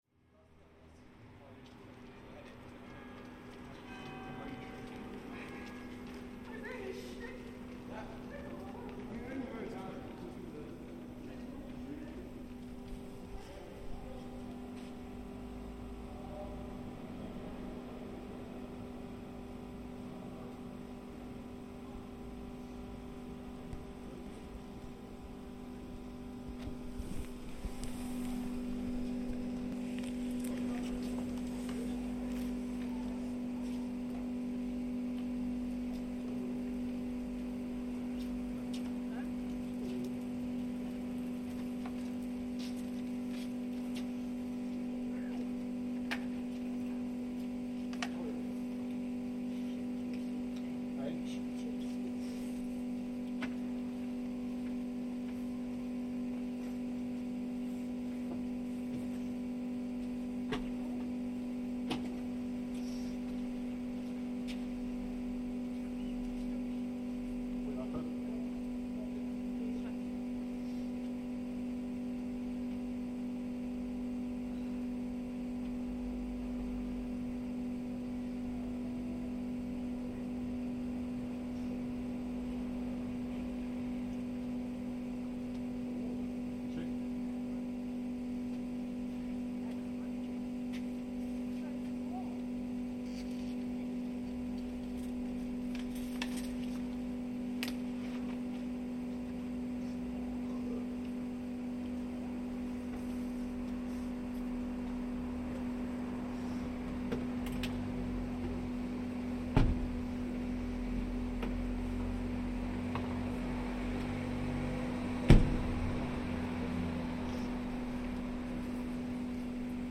One of the unexpected urban soundscapes of the Covid-19 lockdowns has been previously-unheard or unnoticed sounds that had been hidden by the drones of continuous traffic noise emerging into focus.
On this occasion, the pulsing drone from a box on the street (telecoms? air conditioning? who knows?) is the dominant sound all of a sudden, so we recorded it along with several moments of passing life during the third lockdown in the UK.